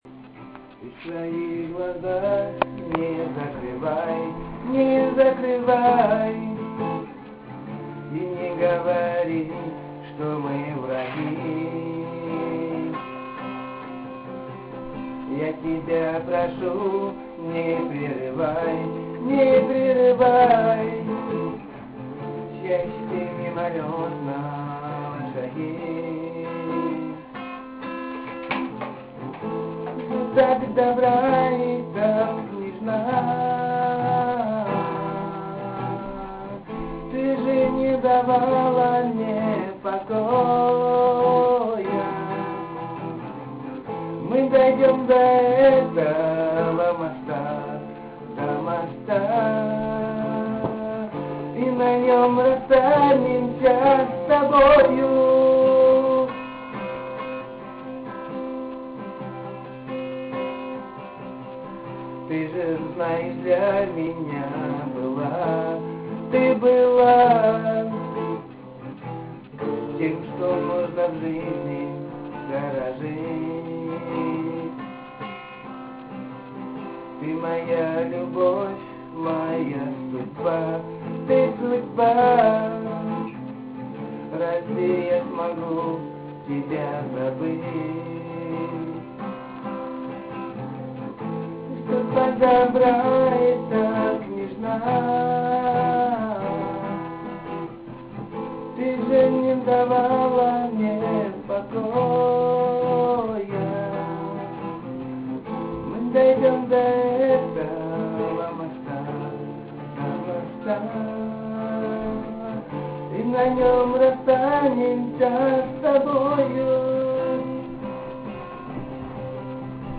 Я пою